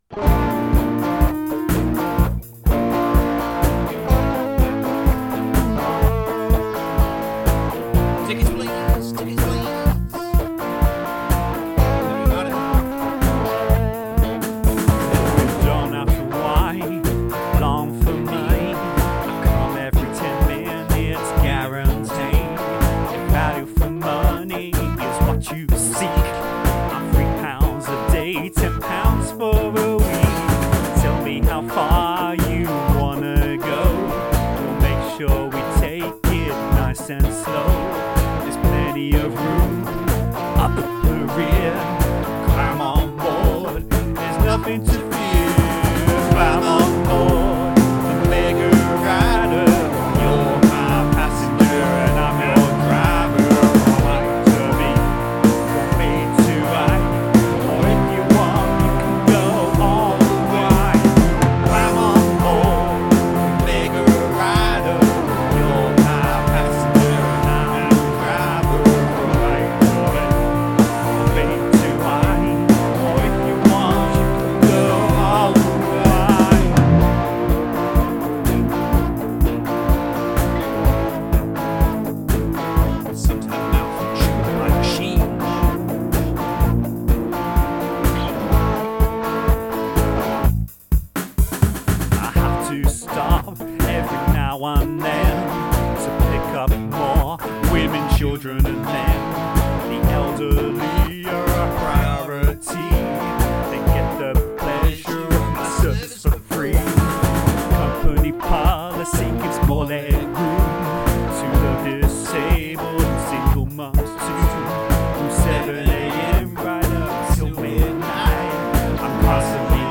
Influenced by Elton John.